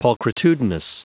Prononciation du mot pulchritudinous en anglais (fichier audio)
Prononciation du mot : pulchritudinous